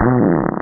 Amiga 8-bit Sampled Voice
bottieburp.mp3